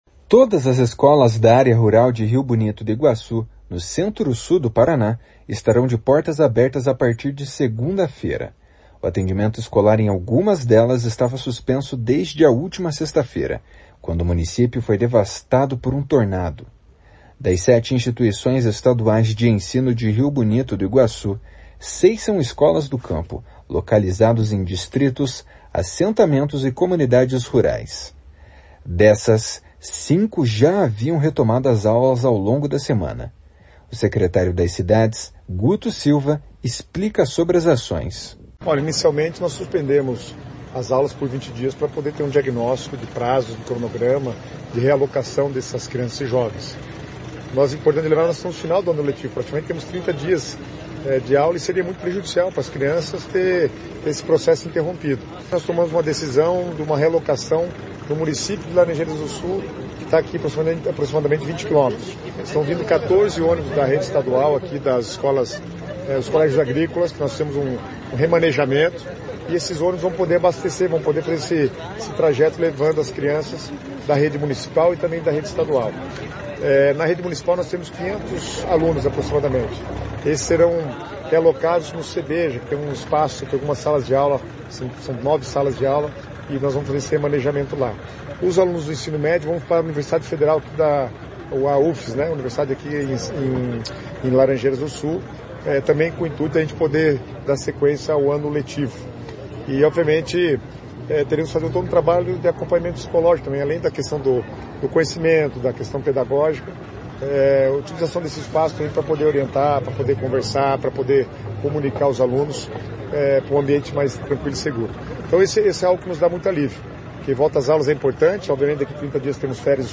O secretário das Cidades, Guto Silva, explica sobre as ações. // SONORA GUTO SILVA //